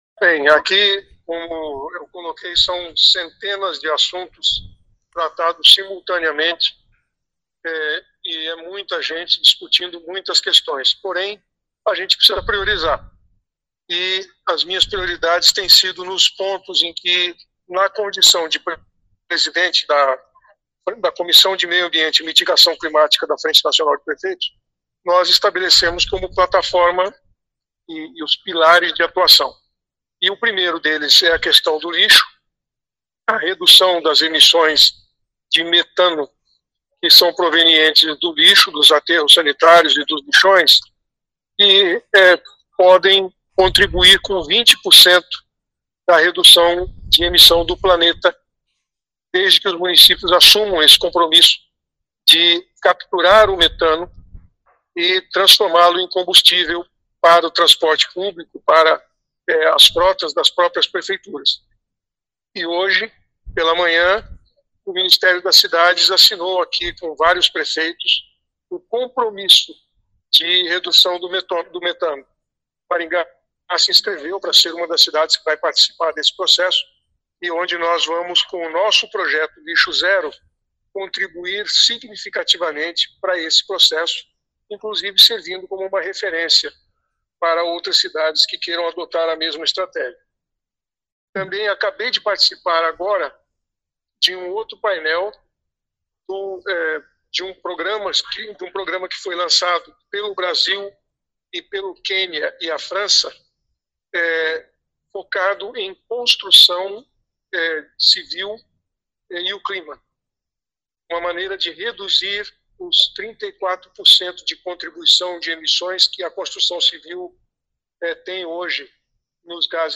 Ouça o que diz o prefeito: